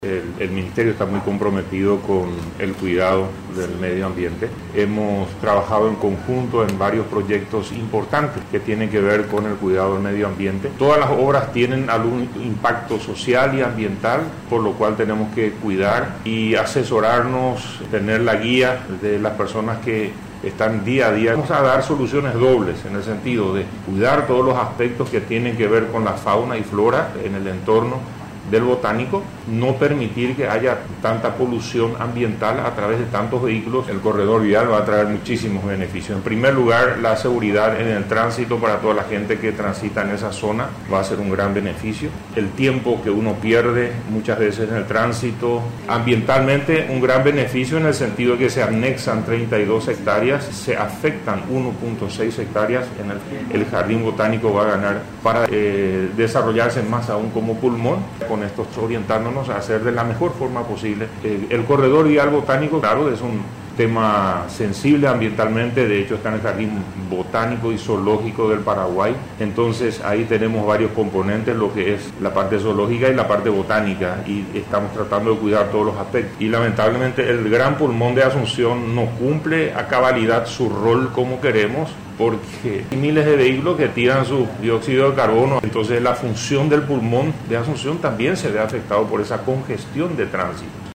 El Ministro de Obras Públicas y Comunicaciones (MOPC) Arnoldo Wiens, informó acerca del plan de construir un viaducto adyacente al predio que unirá la avenida Ñu Guazú, la ruta 3, con la Costanera Norte.